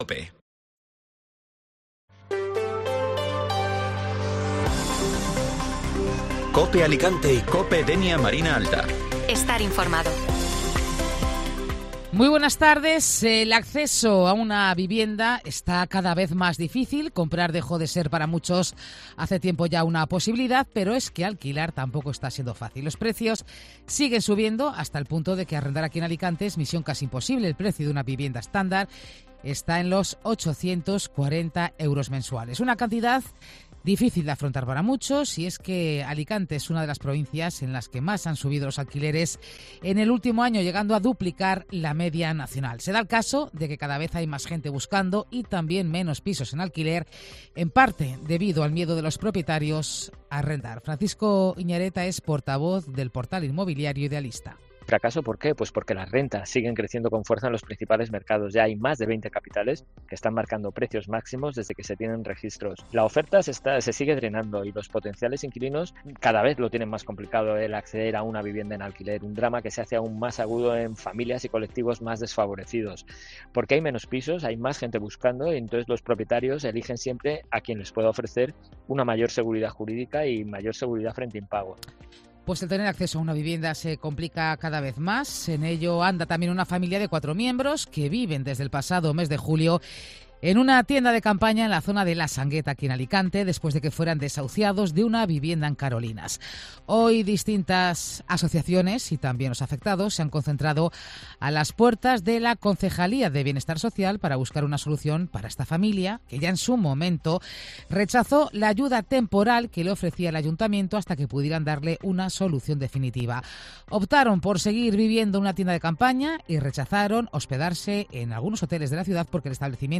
Informativo Mediodía Cope Alicante (Lunes 2 de Octubre)